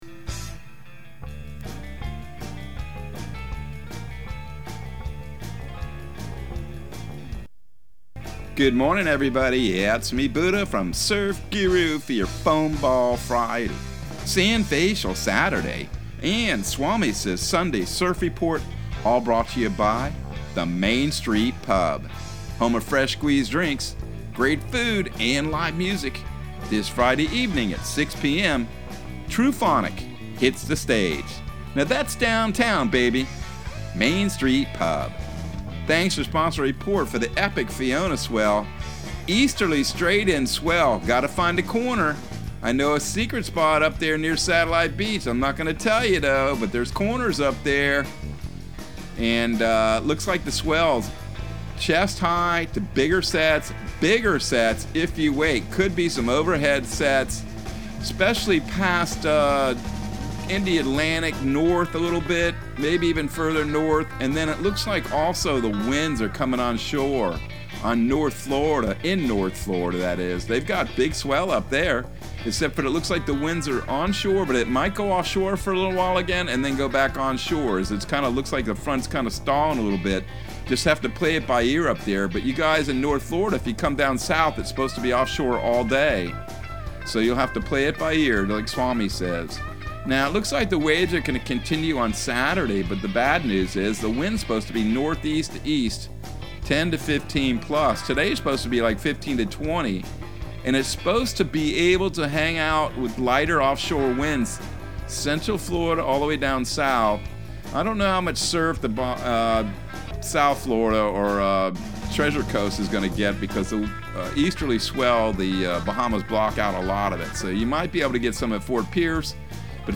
Surf Guru Surf Report and Forecast 09/23/2022 Audio surf report and surf forecast on September 23 for Central Florida and the Southeast.